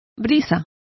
Complete with pronunciation of the translation of breeze.